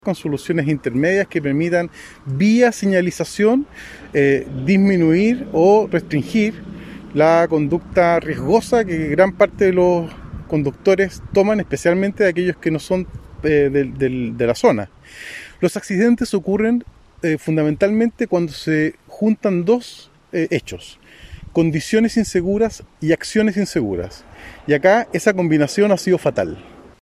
El subsecretario de Obras Públicas, José Herrera, recorrió ambas rutas y tras evidenciar los riesgos que existen comprometió medidas a corto plazo para disminuir la cantidad de accidentes.